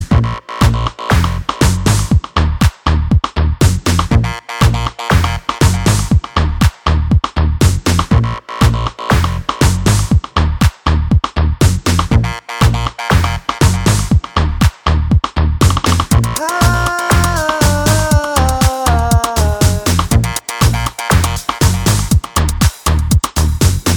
no Backing Vocals Dance 4:14 Buy £1.50